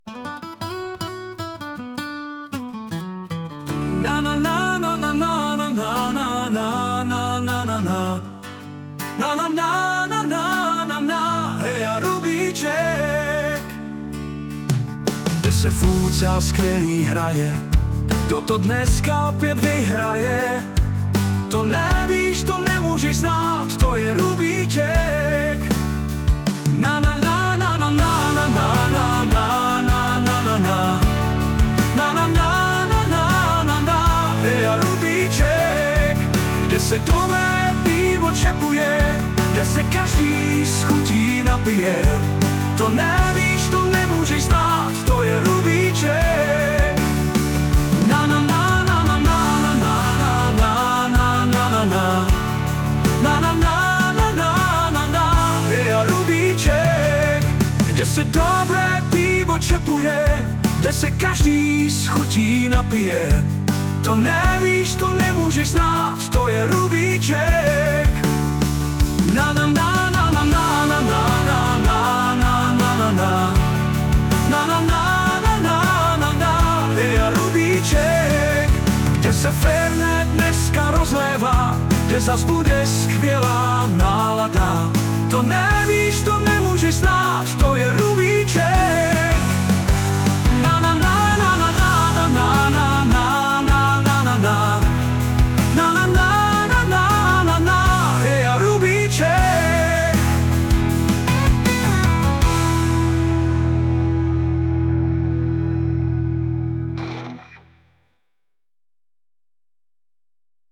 U příležitosti zahájení 39. soutěžní sezóny máme tady další kratší klubový popěvek ve dvou variantách.